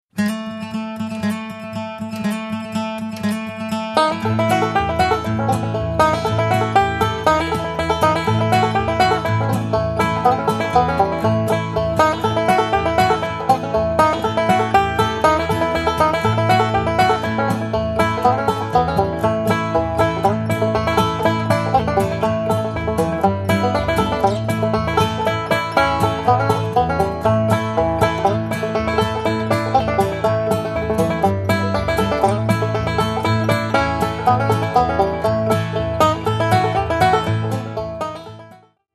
--folk-bluegrass music